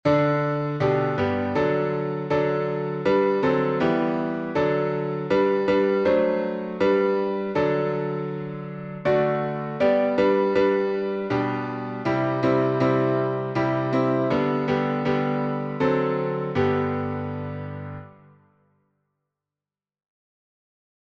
Arranged by Lowell Mason (1792-1872).Key signature: G major (1 sharp)Time signature: 3/2Meter: 8.6.8.6.(C.M.)Public Domain1.